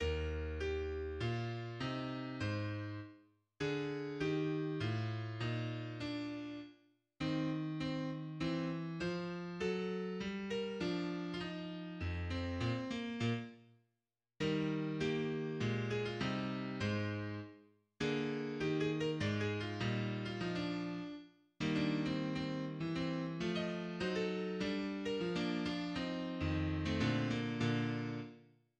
2. Andante, en mi bémol majeur, à
L'Andante est d'une expression profondément recueillie et méditative, s'ouvrant sur un dialogue alterné entre le piano et l'orchestre, avant de connaître un développement de caractère fusionnel, plein de douceur, entre les deux parties.
Introduction de l'Andante (Violon 1, piano):